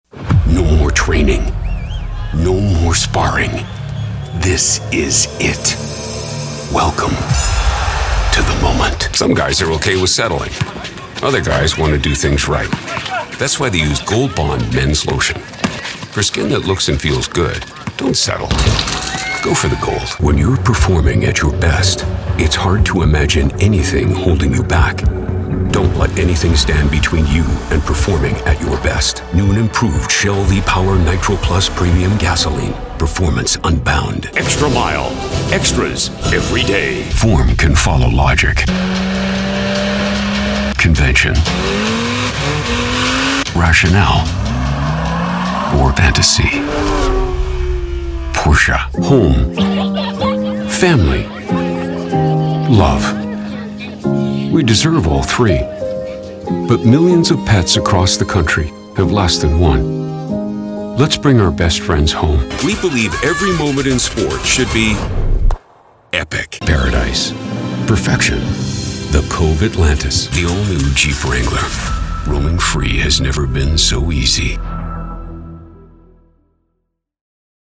English (Canadian)
Confident
Grounded
Serious